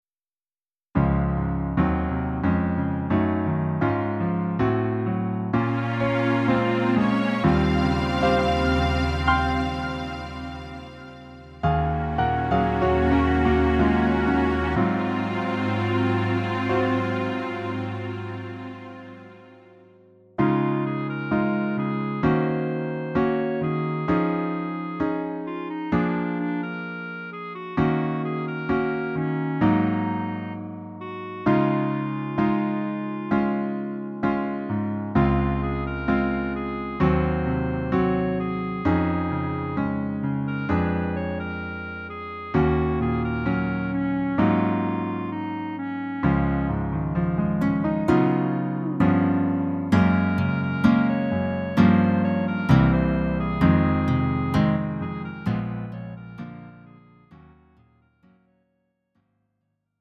음정 -1키 4:27
장르 가요 구분 Lite MR
Lite MR은 저렴한 가격에 간단한 연습이나 취미용으로 활용할 수 있는 가벼운 반주입니다.